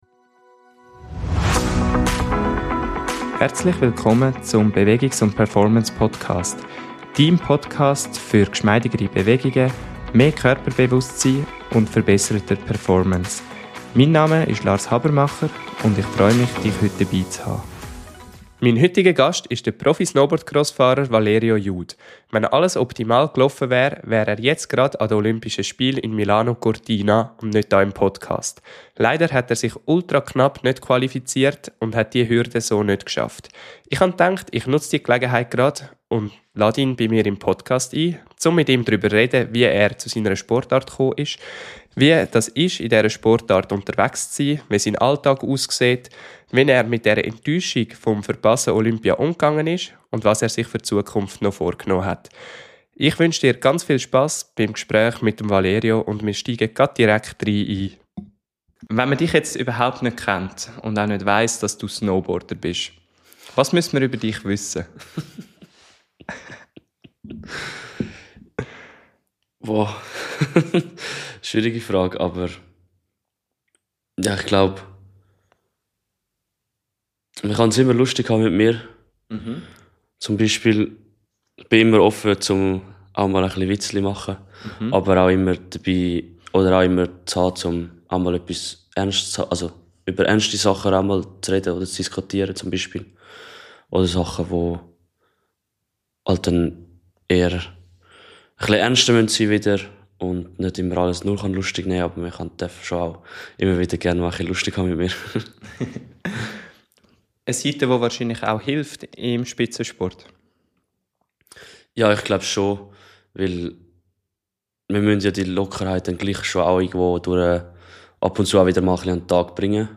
Wir sprechen über finanzielle Realität, verpasste Olympia-Träume, Zweifel – und darüber, was bleibt, wenn Resultate ausbleiben. Ein persönliches Gespräch über Identität, Druck und die Frage, wer man ist, wenn der Wettkampf vorbei ist.